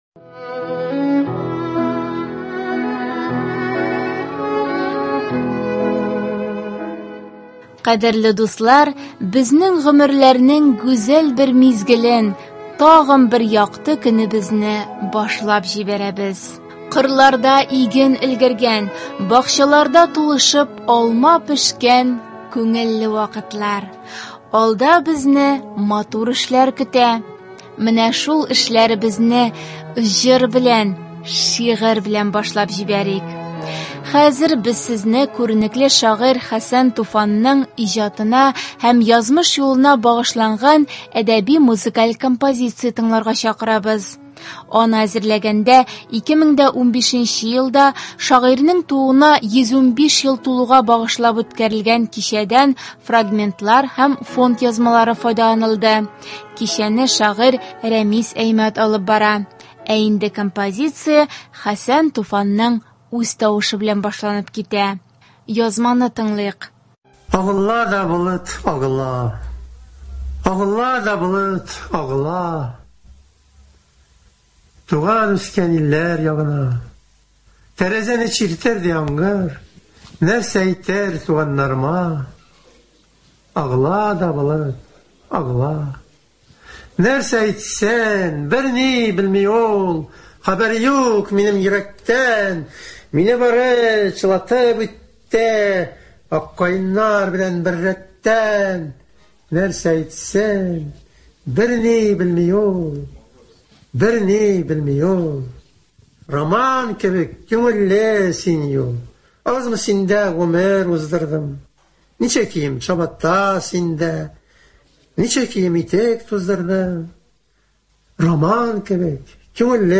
Хәзер без сезне күренекле шагыйрь Хәсән Туфанның иҗатына һәм язмыш юлына багшыланган әдәби-музыкаль композиция тыңларга чакырабыз. Аны әзерләгәндә 2015 елда шагыйрьнең тууына 115 ел тулуга багышлап үткәрелгән кичәдән фрагментлар һәм фонд язмалары файдаланылды.